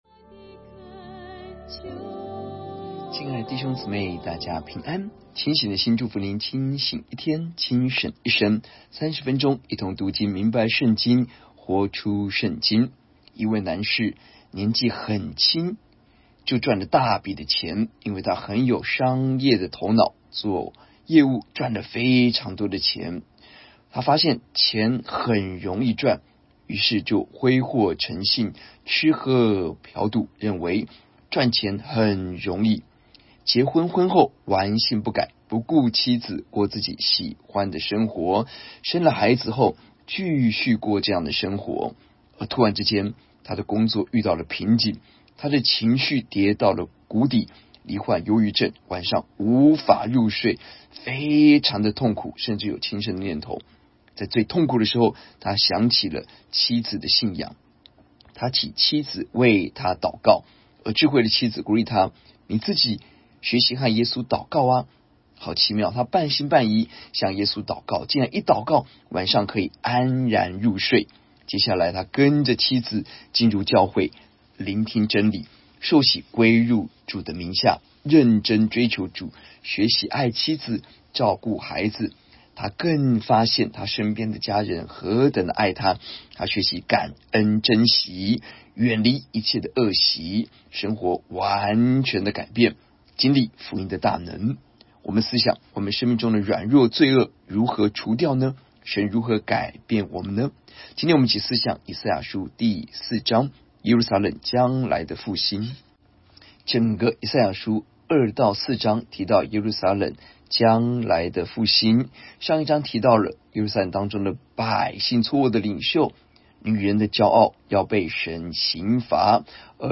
出處：佳音LOVE聯播網 主講人：